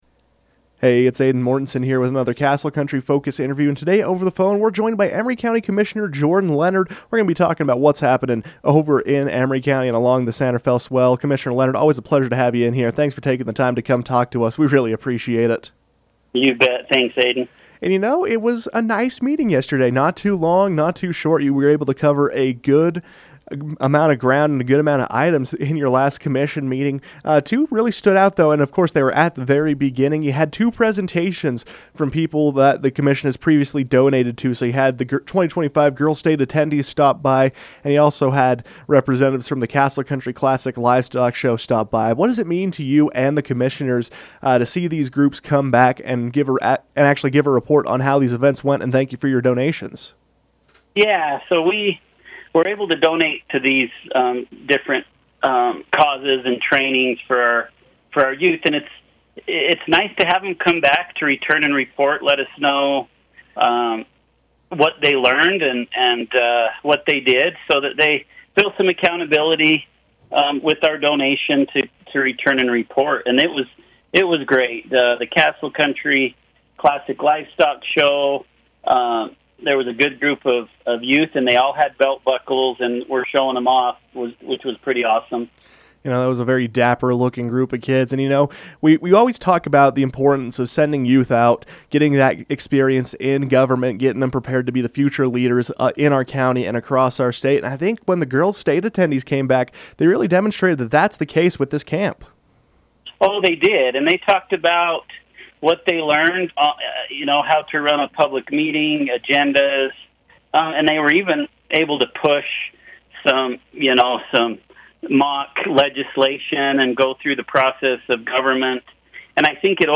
Emery County Commissioner joined the KOAL newsroom to discuss what's happening along the San Rafael Swell.